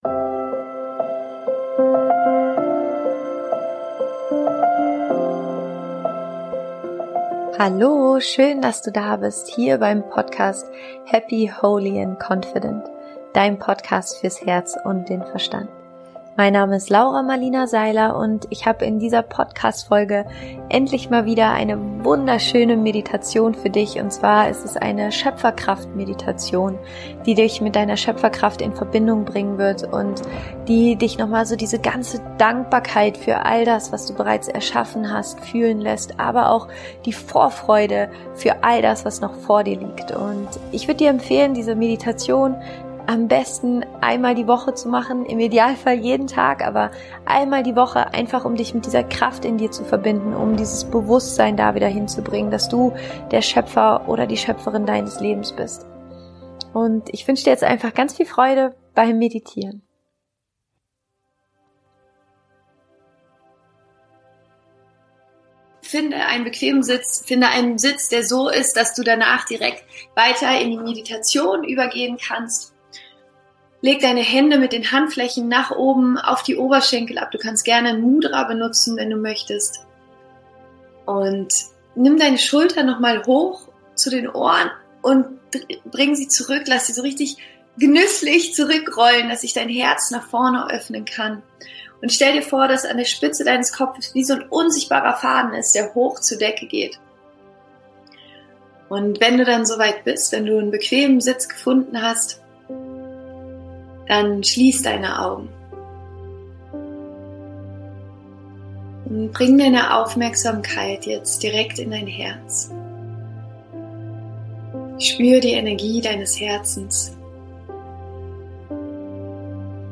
Geleitete Meditation - Erlebe deine Schöpferkraft